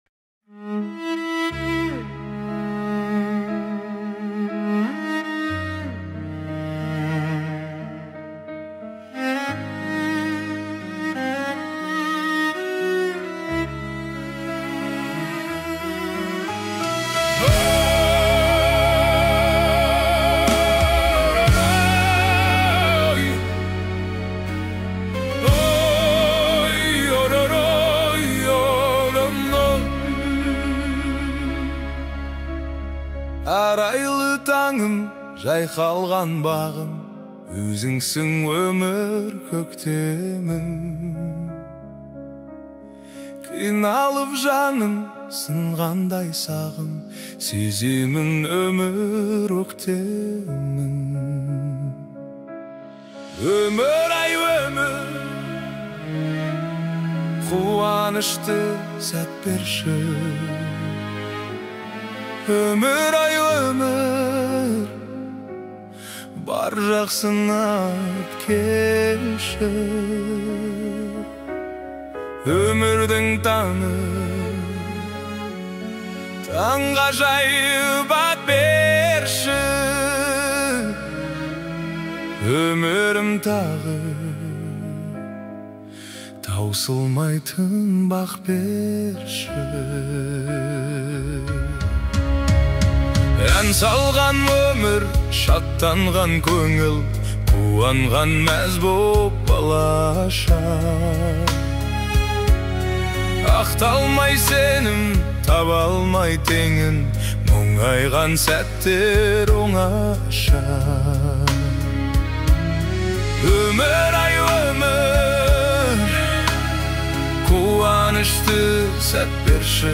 Жасанды интеллекттің орындауында
ән жаңа үнмен, байсалды әуенмен